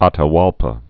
tə-wälpə) also A·ta·ba·li·pa (-bälĭ-pä) 1502?-1533.